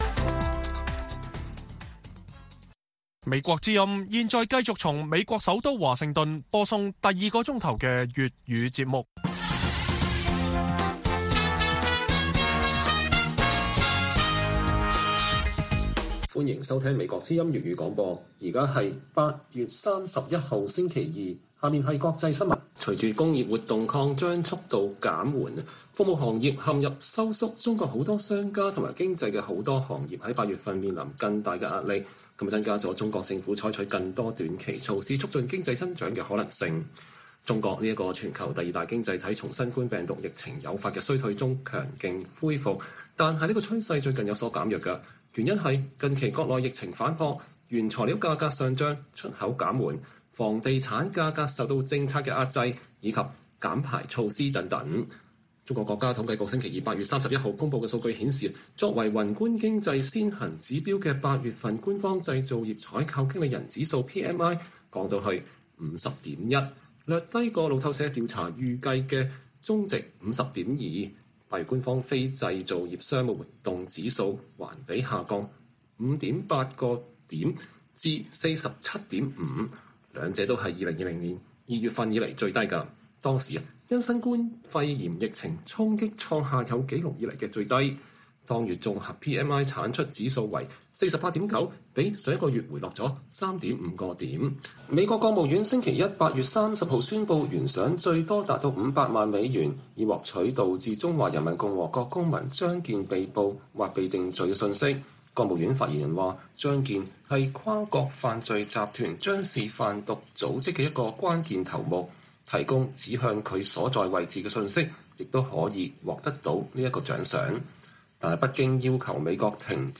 粵語新聞 晚上10-11點: 中國工業活動減緩服務業收縮